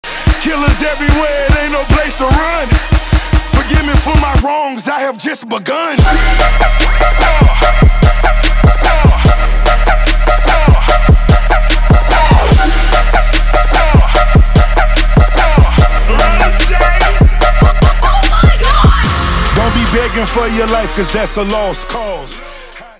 • Качество: 320, Stereo
Electronic
Trap
Bass
Dubstep